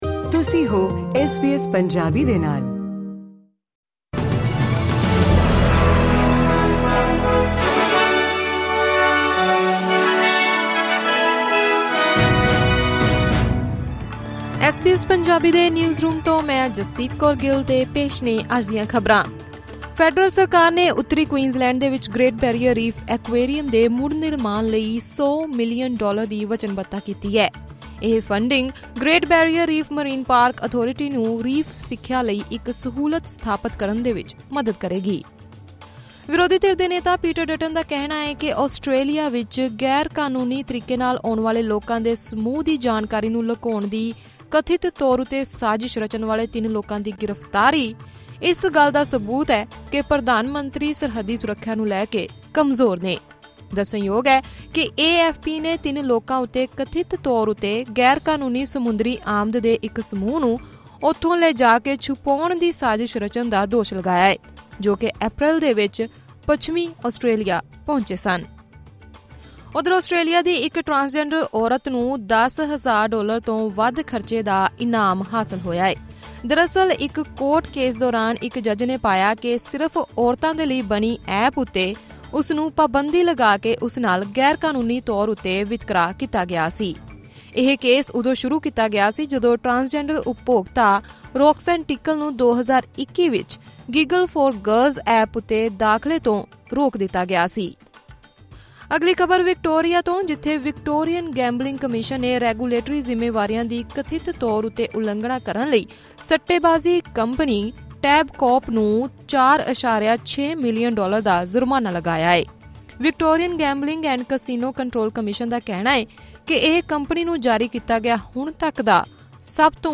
ਐਸ ਬੀ ਐਸ ਪੰਜਾਬੀ ਤੋਂ ਆਸਟ੍ਰੇਲੀਆ ਦੀਆਂ ਮੁੱਖ ਖ਼ਬਰਾਂ: 23 ਅਗਸਤ 2024